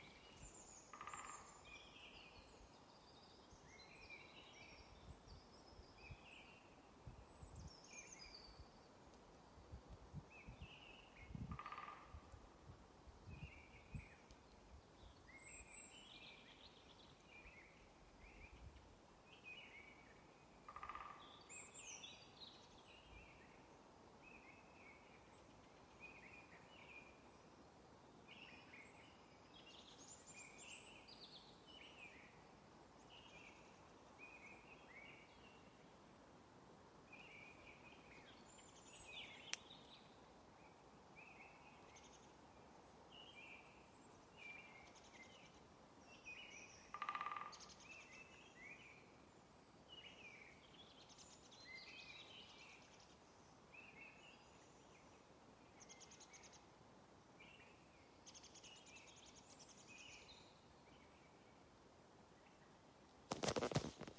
Putns (nenoteikts), Aves sp.
Administratīvā teritorijaValkas novads
StatussUzturas ligzdošanai piemērotā biotopā (B)